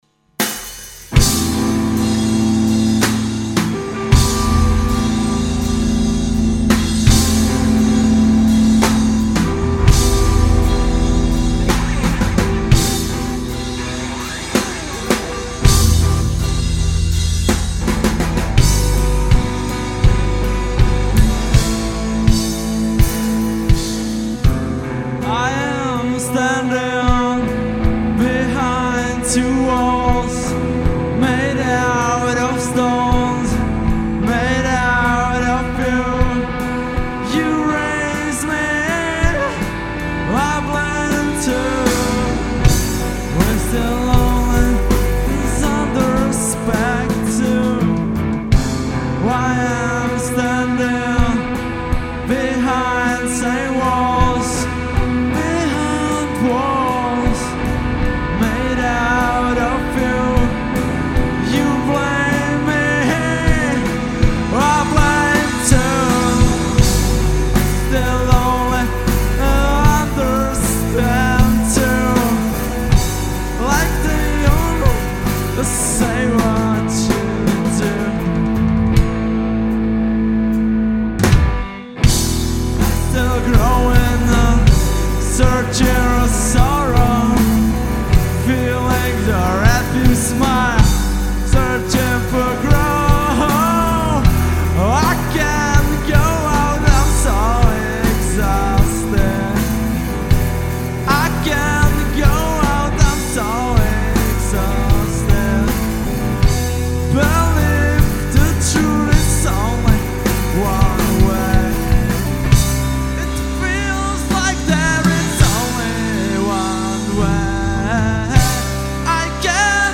Žánr: Indie/Alternativa
zpěvy, kytary
baskytara
bicí